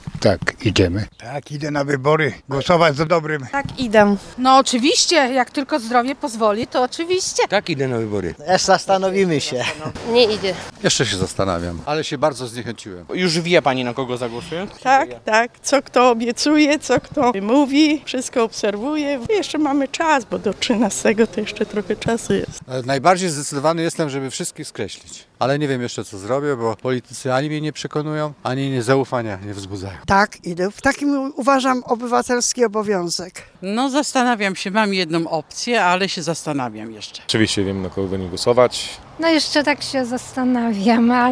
Zielonogórzanie pójdą do urn? [SONDA RZG]
W związku z tym zapytaliśmy zielonogórzan czy pójdą na wybory oraz czy wiedzą już na kogo zagłosują?